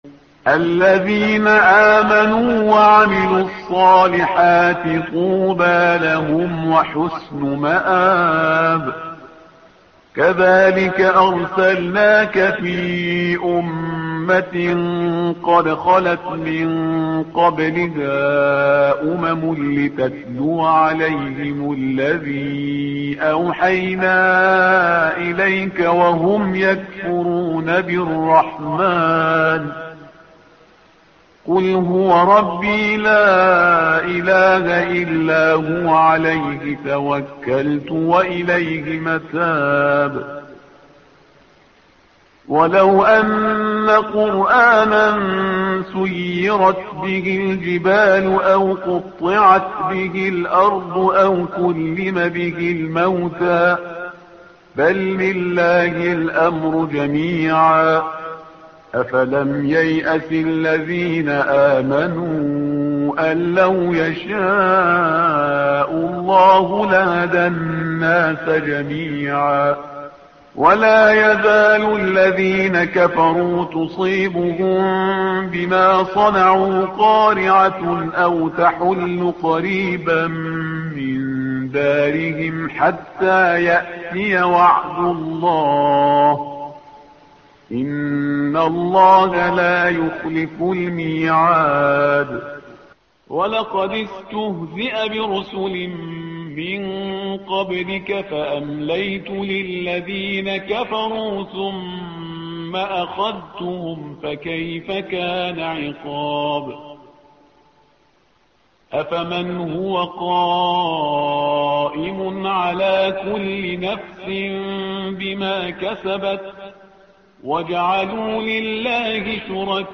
الصفحة رقم 253 / القارئ